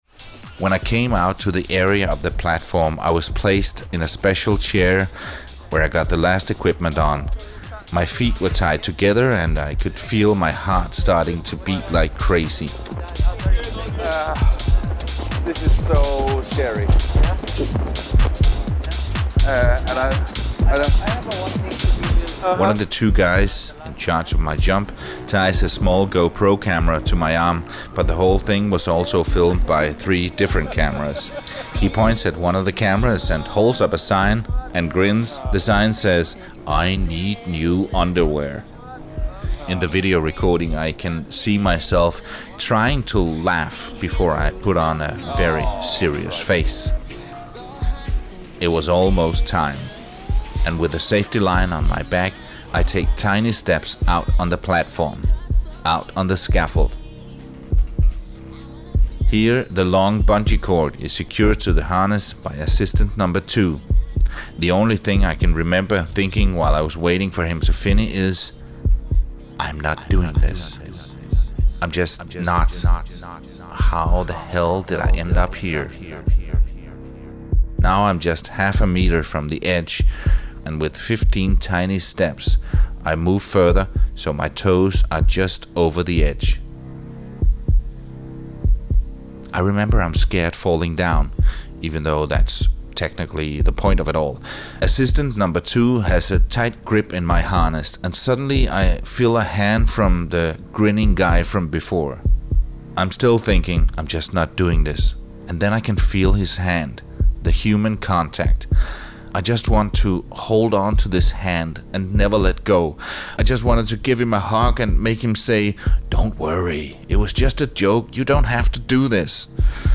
It’s filled with giant casinos, neon lights and a fake Eiffel Tower, but even more exciting is the view from my hotel room: Macau Tower, the world tallest bungy jump. So you will hear me screaming in this clip.